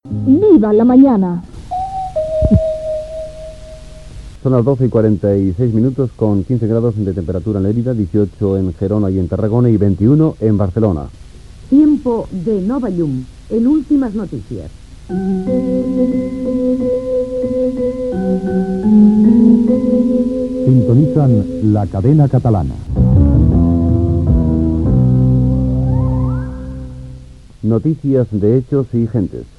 Indicatiu del programa, informació meteorològica, indicatiu de l'emissora i dona pas a les notícies
Entreteniment